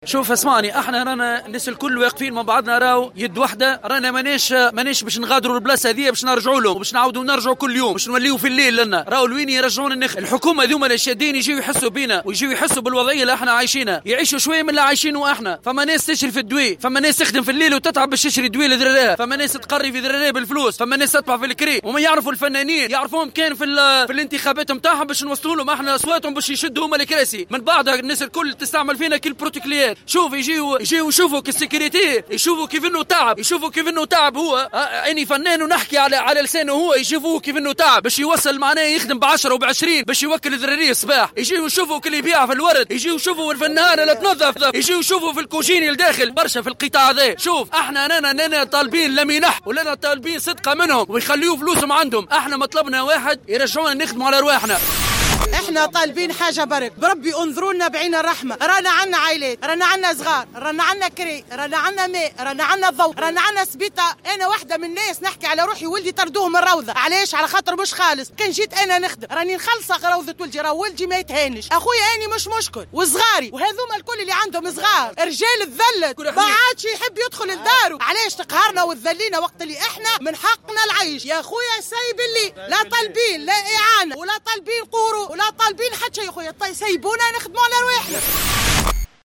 نظم اليوم أصحاب المهن الليلية وقفة إحتجاجية أمام مقر ولاية سوسة، تنديدا بالتمديد في حظر التجوّل وما رافق ذلك من تعطّل لنشاطهم وتدهور لوضعياتهم الاجتماعية.